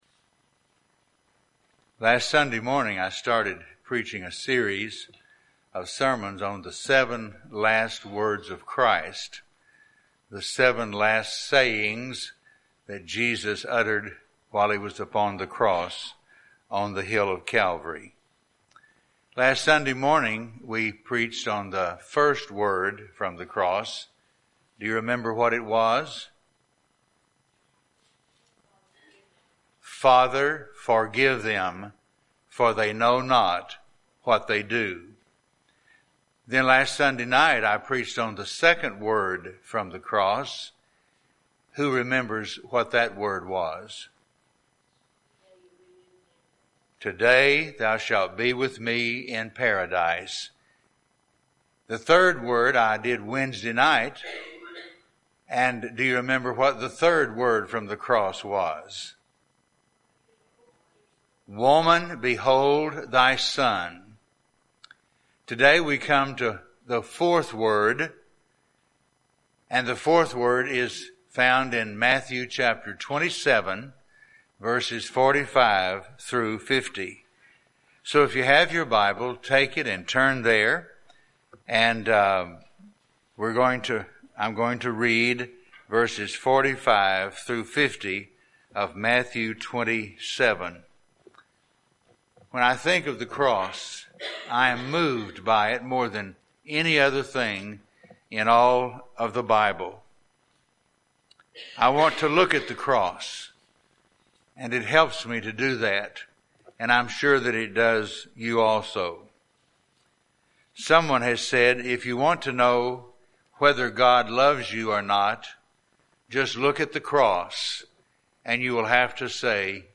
Morning message from Matthew 27:45-50.